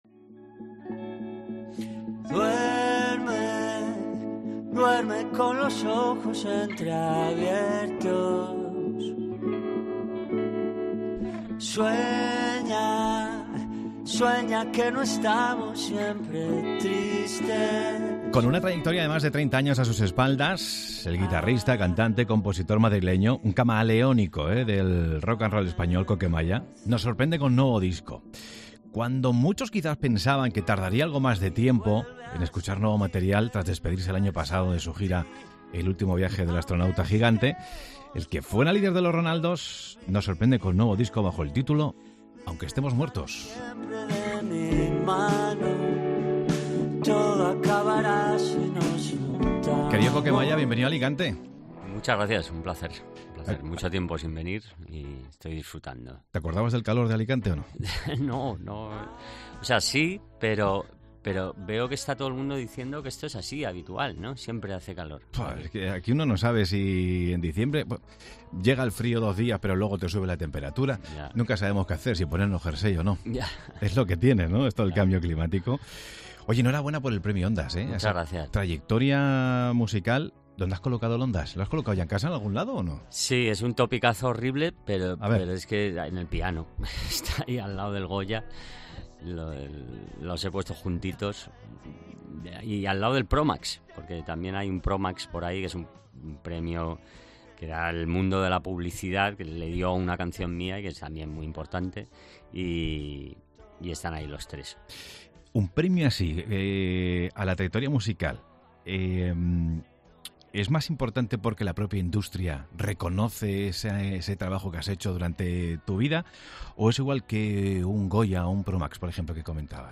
El artista madrileño presenta su nueva gira que llegará el 20 de enero a Alicante y nos cuenta en Mediodía COPE Alicante su metolodogía para componer y elegir los temas del disco.